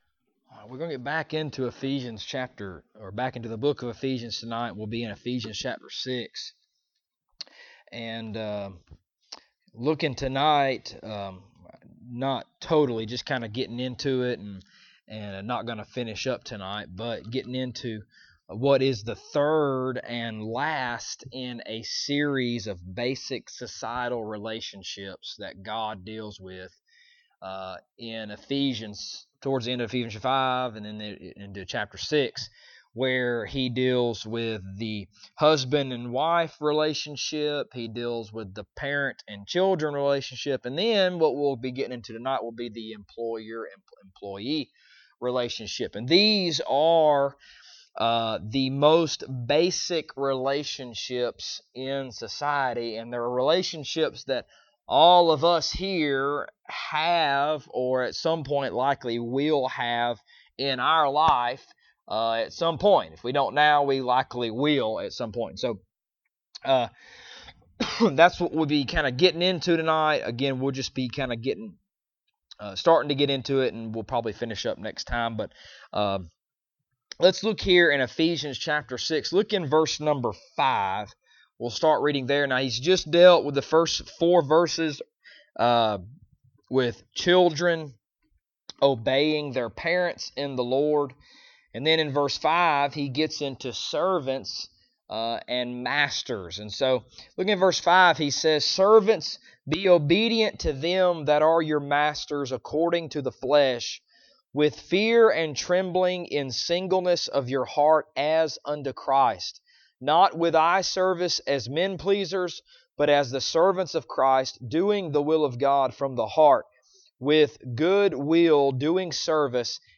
Ephesians Passage: Ephesians 6:5-7 Service Type: Wednesday Evening Topics